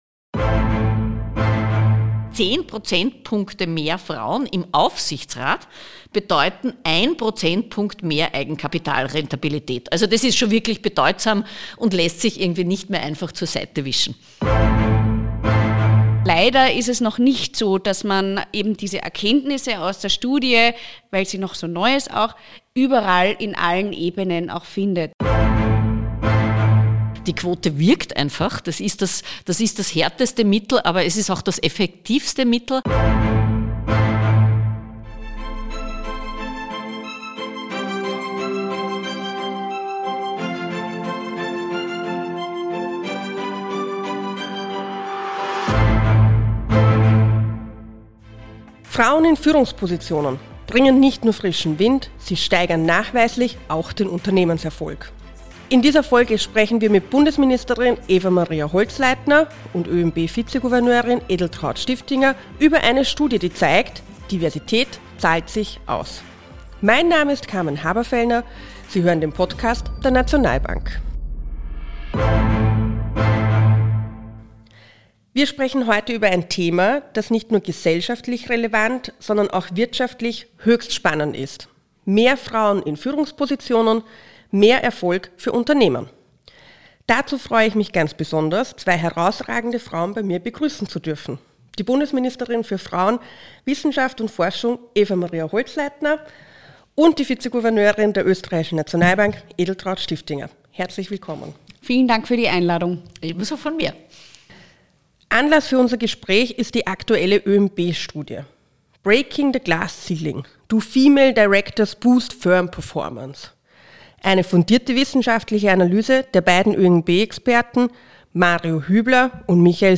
Sie basiert auf Daten des S&P-500 der letzten 20 Jahre und kommt zu dem Ergebnis, dass mehr Frauen im Aufsichtsrat nachweislich auch den wirtschaftlichen Erfolg eines Unternehmens steigern. In dieser Folge des Nationalbank-Podcast sprechen wir mit der Bundesministerin für Frauen, Wissenschaft & Forschung, Eva Maria Holzleitner und OeNB-Vize-Gouverneurin Edeltraud Stiftinger über die konkreten Studienergebnisse und ihre Auswirkungen.